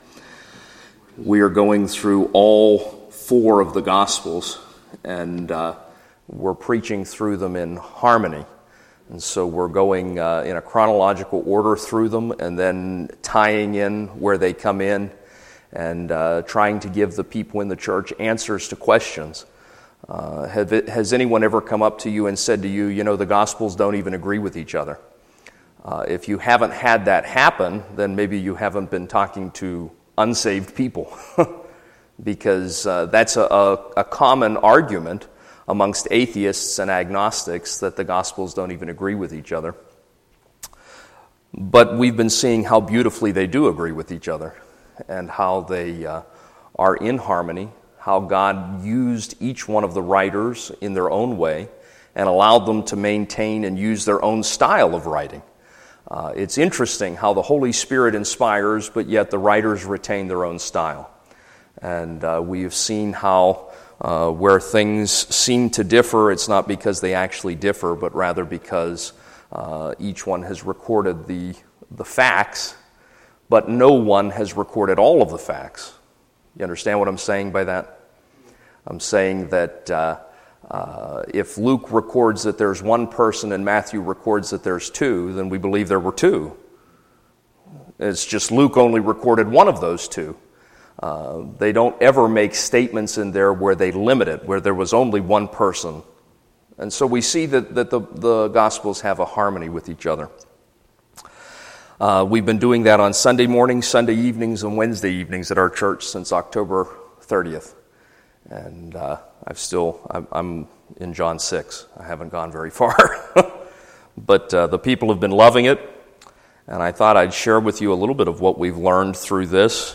Session: Morning Devotion